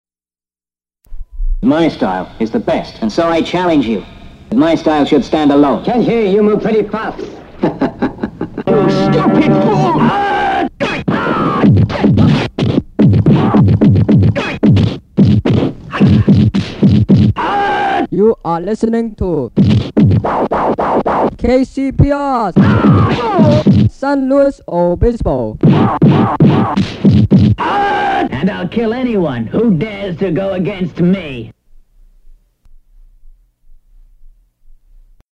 The recording includes sound effects and dialogue from classic kung fu films.
Form of original Audiocassette